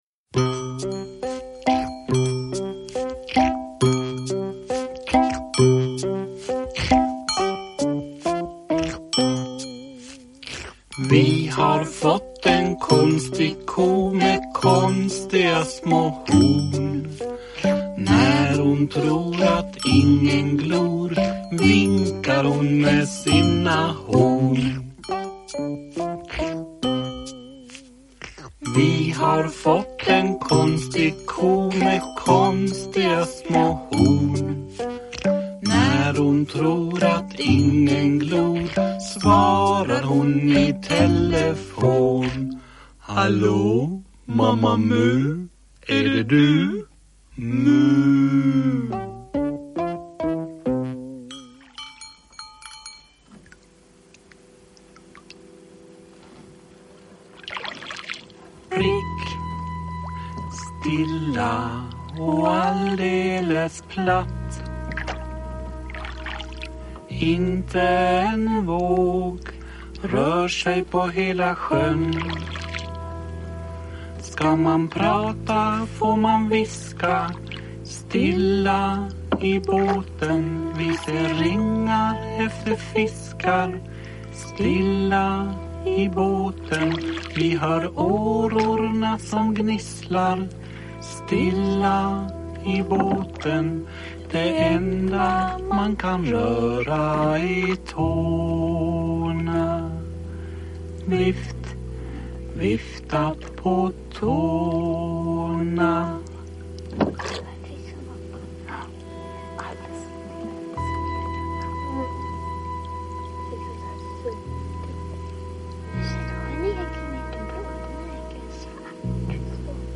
Uppläsning med dramatisering. Innehåller följande berättelser: Mamma mu fiskar; Gammelnalle; Bonden äter lunch; Kråkan ramlar ner; Mamma mu gungar; Det kom blod; Mamma Mu dansar.
Uppläsare: Jujja Wieslander